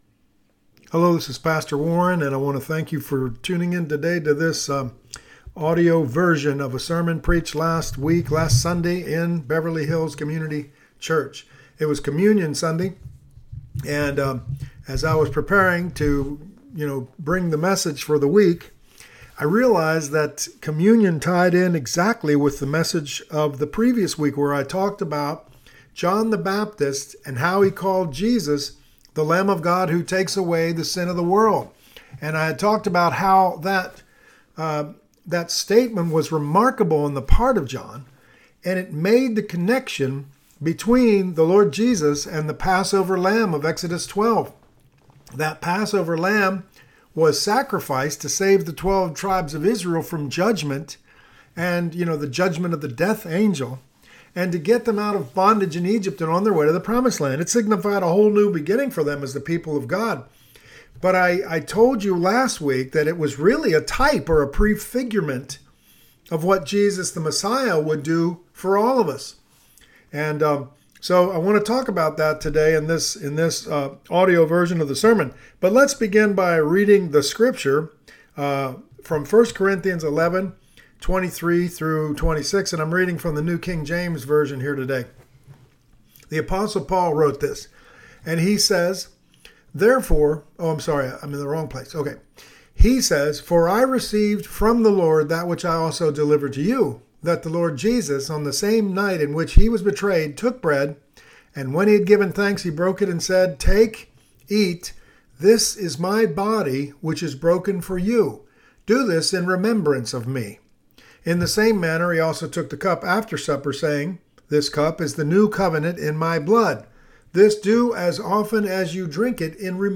Communion Sunday Message
This is the audio version of last Sunday’s message at Beverly Hills Community Church, dealing with the significance of holy communion.